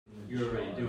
Live from the Town of Catskill: November 19, 2025 Catskill Town Board Meeting Public Hearing w (Audio)